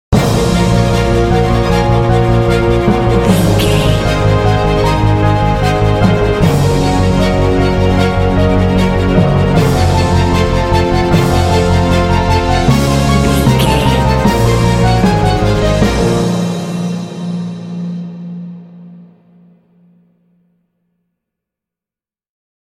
Epic / Action
Fast paced
Aeolian/Minor
B♭
orchestra
piano
cinematic
underscore